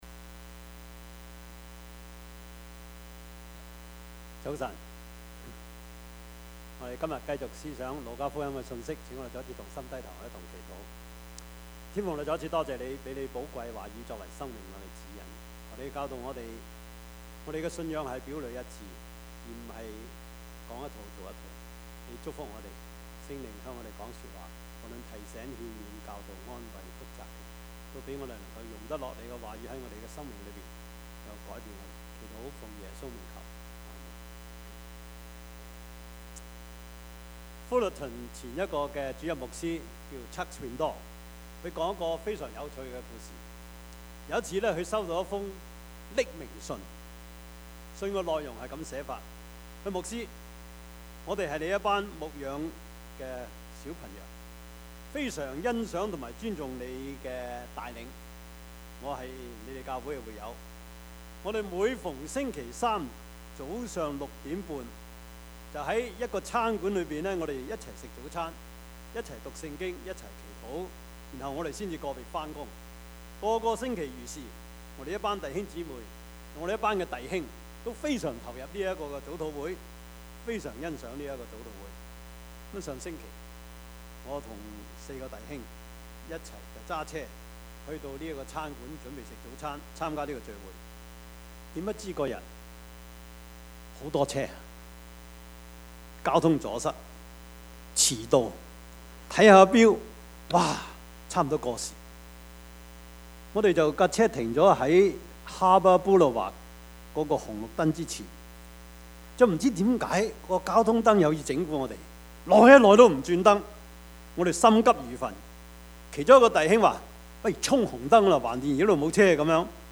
Service Type: 主日崇拜
Topics: 主日證道 « 為父為牧 怕甚麼 »